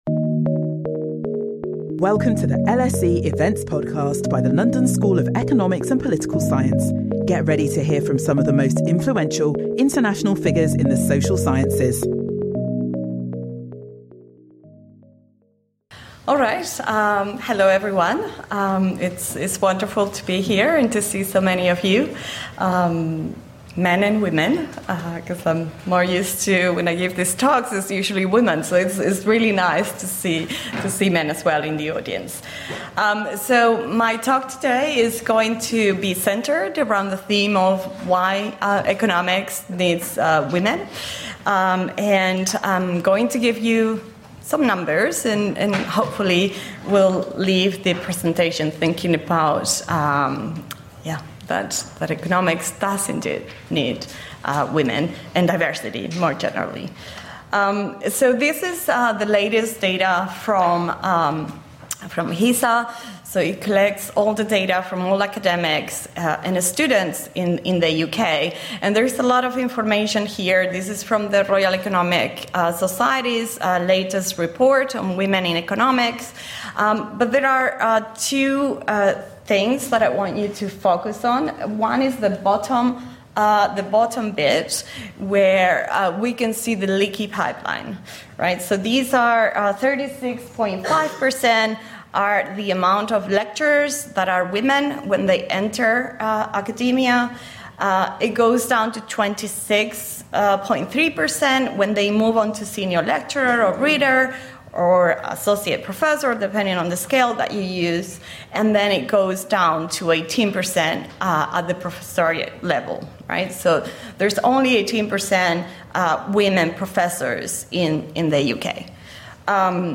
Our speakers share research on women in economics, insights from their own experiences and advice for those wanting to make a career in economics.